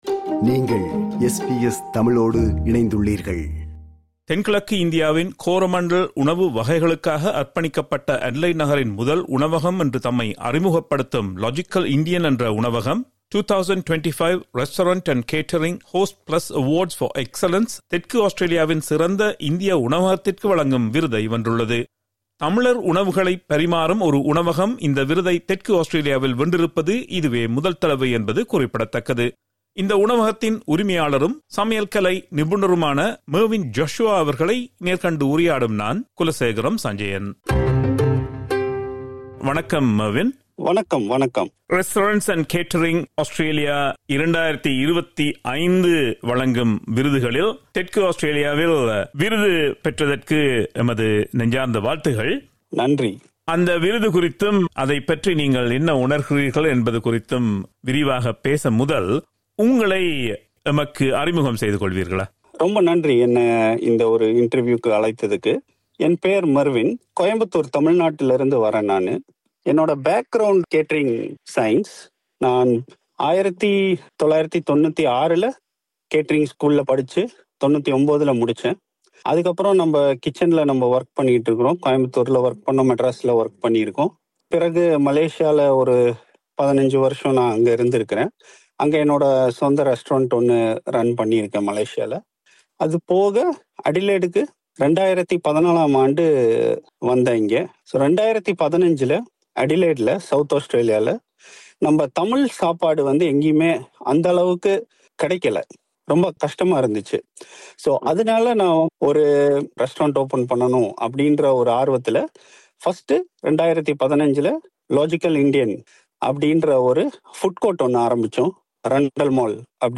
நேர்காண்கிறார்